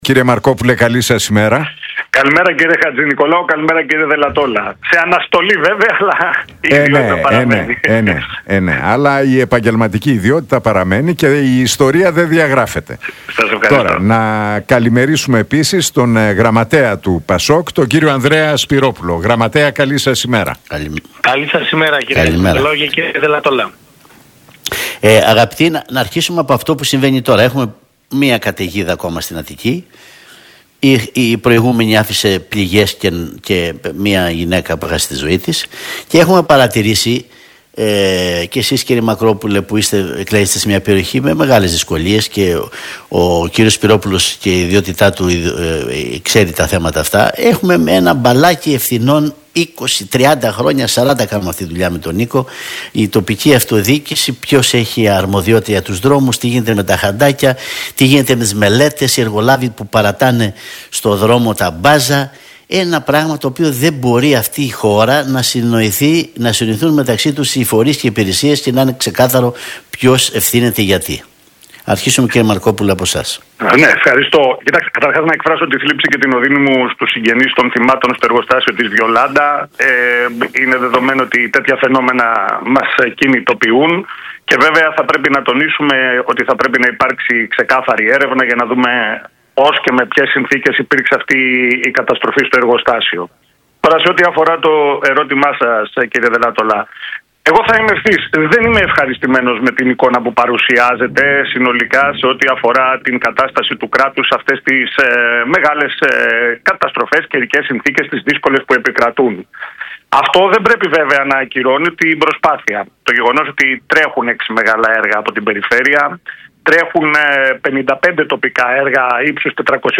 Αντιπαράθεση Μαρκόπουλου